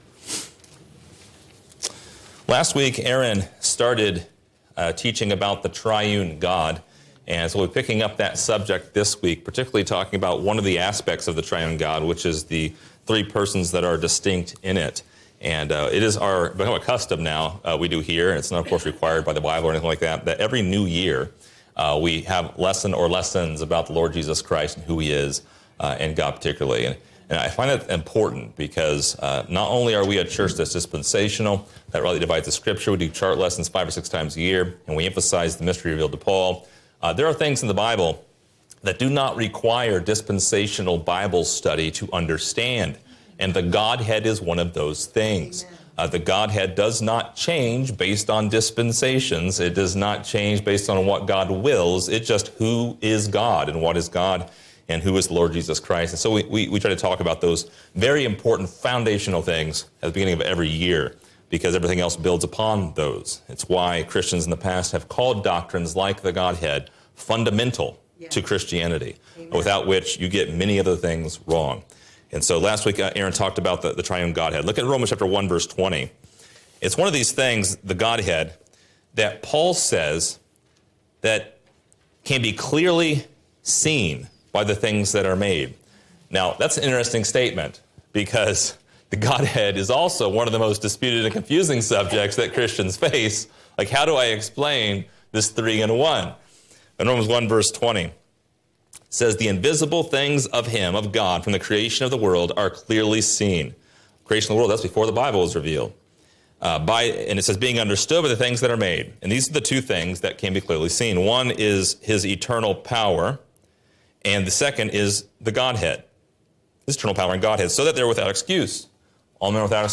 Download MP3 | Download Outline Description: One of the many debates about the triune Godhead is whether or not there are three distinct persons – Father, Son, and Holy Ghost – in one God. This lesson details how and why the three persons are distinct, along with plenty of scripture references that show the distinctions between the three persons of the Godhead.